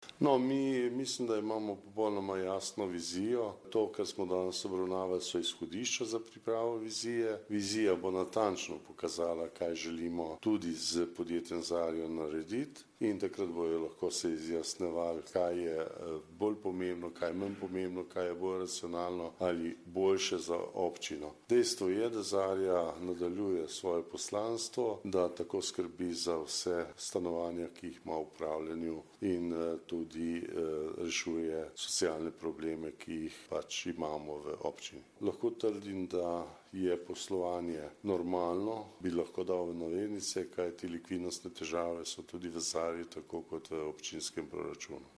Župan Alojzij Muhič o zadnjih pogovorih v zvezi z izgradnjo Mestne večnamenske dvorane Portoval